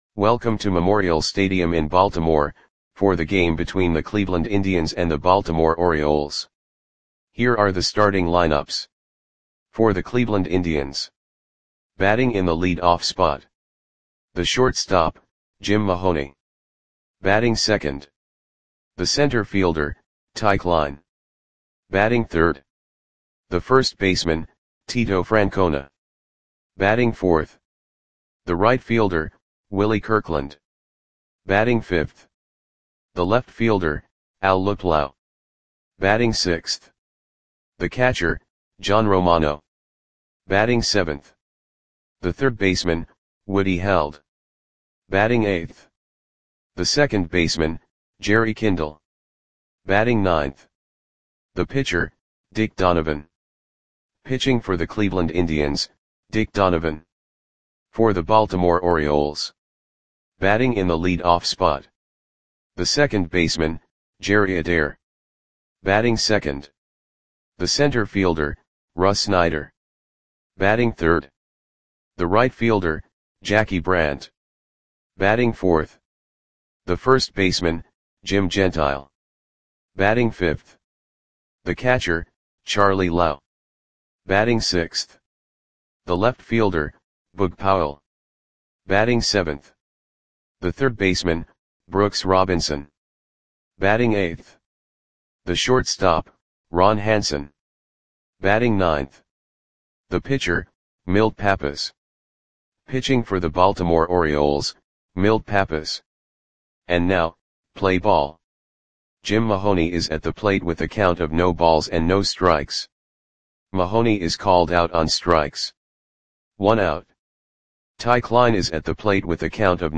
Audio Play-by-Play for Baltimore Orioles on August 17, 1962
Click the button below to listen to the audio play-by-play.